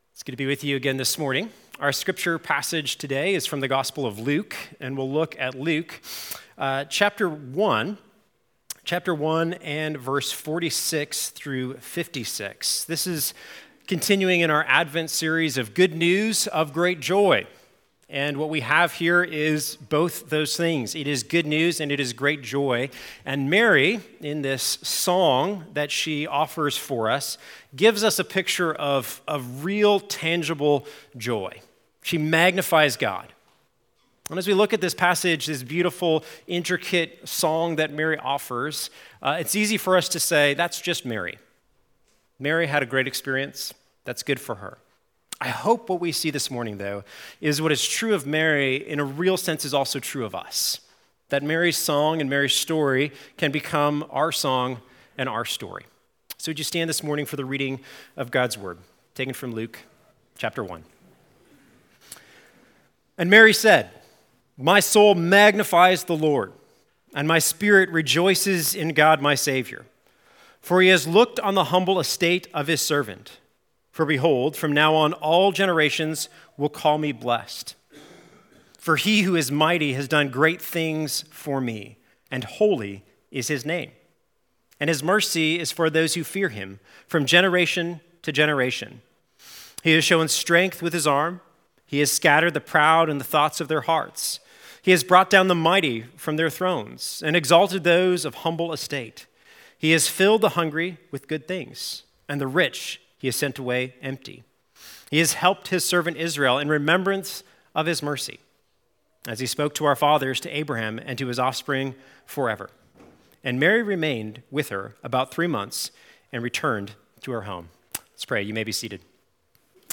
Sermons from Trinity Presbyterian Church, PCA, Boerne, Texas
Sermons